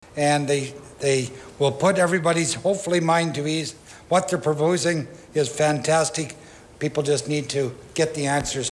At Monday’s council meeting Councillor Garnet Thompson said many residents in the west end neighbourhood have visited the church asking questions about the development and the staff is more than happy to answer them.